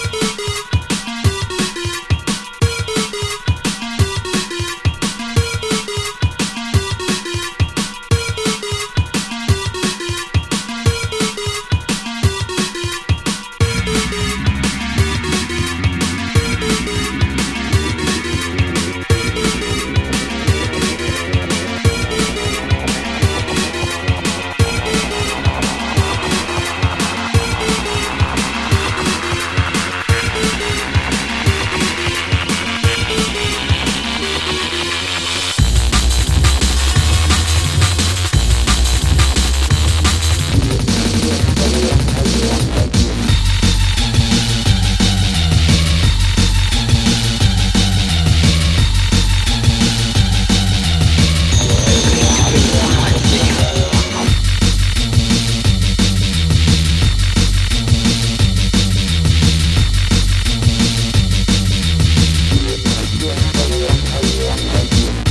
Drum N Bass